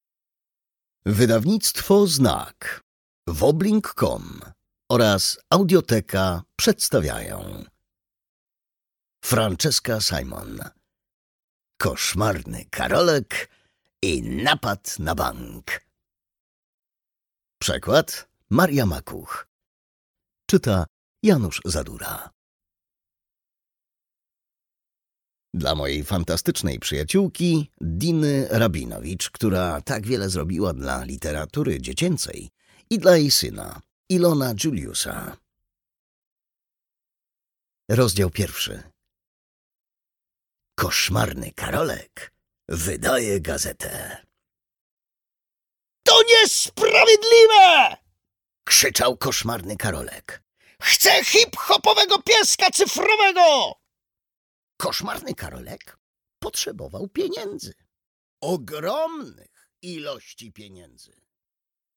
Koszmarny Karolek i napad na bank - Simon Francesca - audiobook + książka